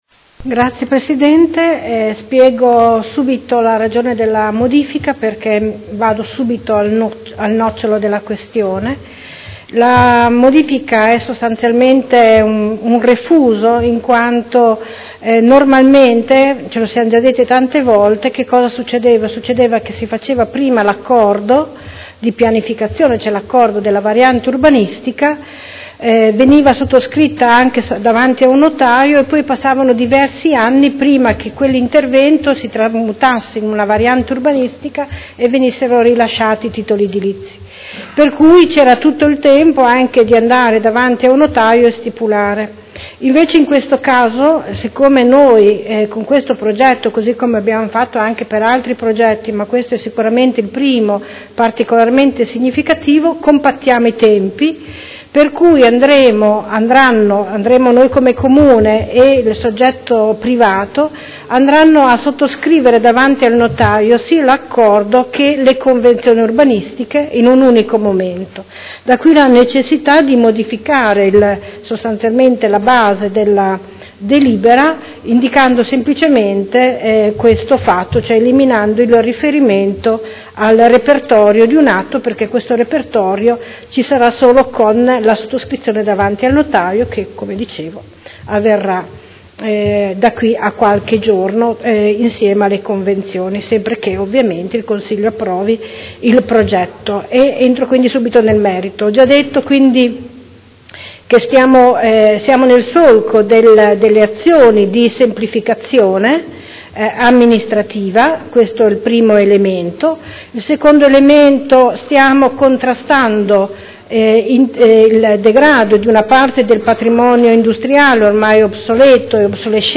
Anna Maria Vandelli — Sito Audio Consiglio Comunale
Seduta del 19/05/2016.